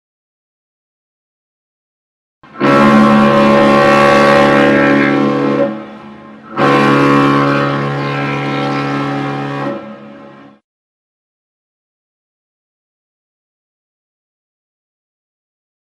shiphorn.mp3